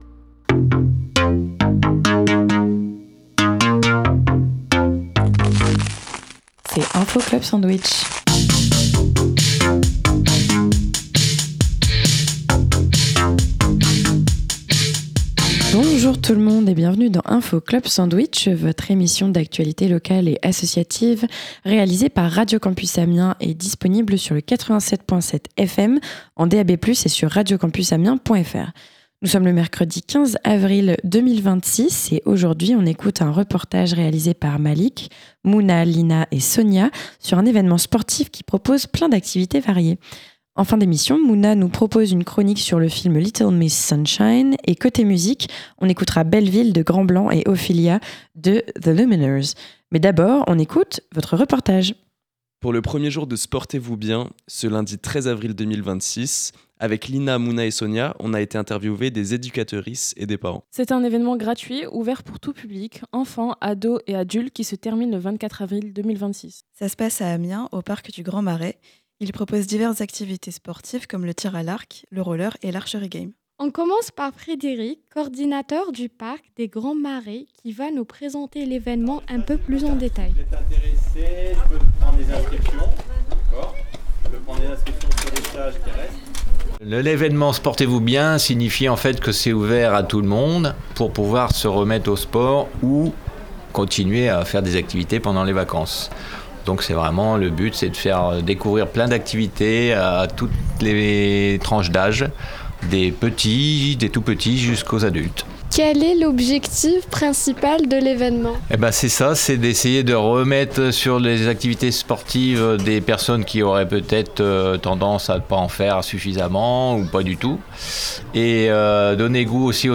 Aujourd’hui dans l’Info Club Sandwich, on écoute un reportage sur l’événement Sportez-vous bien !, organisé par Amiens Métropole, qui a lieu jusqu’au vendredi 24 avril au parc du Grand Marais. Au programme, des stages pour les enfants, et des activités pour les adultes et tout public.